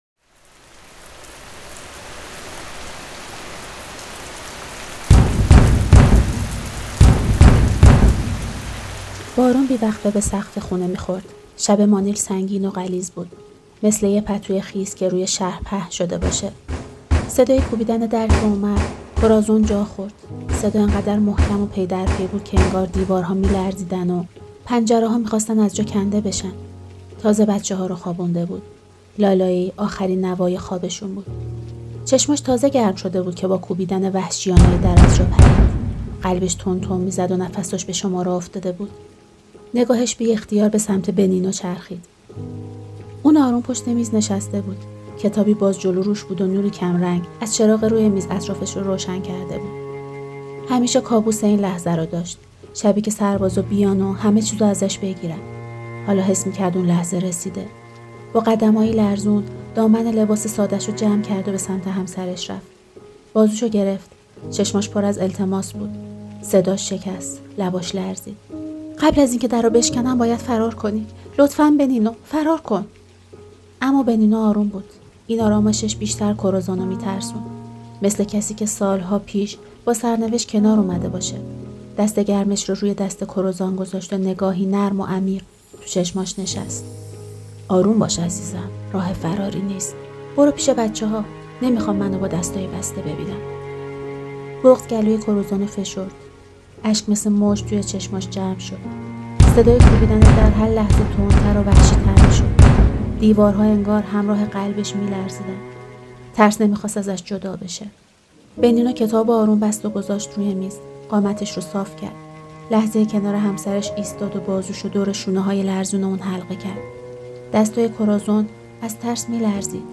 پادکست داستانی متادخت در اپیزودی ویژه، این بار تمرکز خود را بر یکی از درخشان‌ترین این چهره‌ها قرار داده است: کورازون آکینو، زنی که از خانه‌داری به قله‌ ریاست‌جمهوری آسیا رسید!